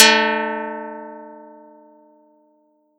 Audacity_pluck_6_13.wav